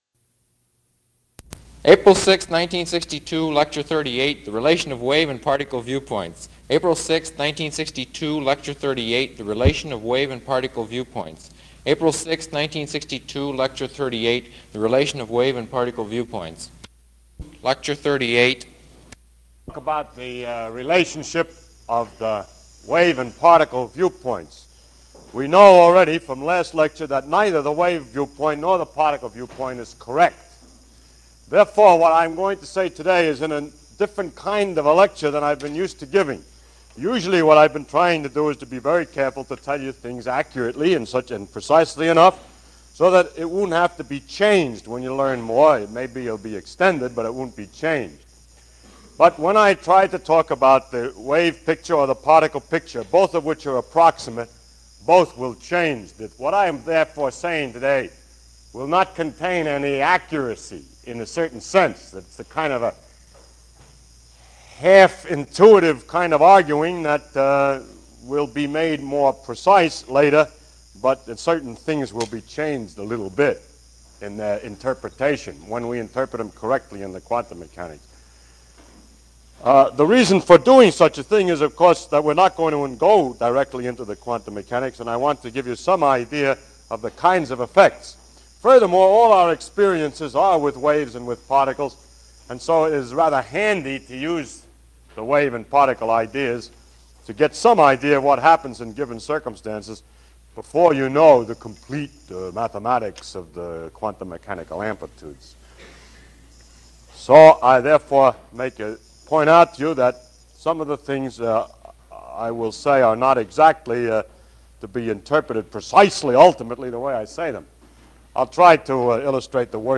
◄ ▲ ► A A A SUMMARY 파인만 물리 강의 녹음 play stop mute max volume 38 The Relation of Wave and Particle Viewpoints 38–1 Probability wave amplitudes In this chapter we shall discuss the relationship of the wave and particle viewpoints.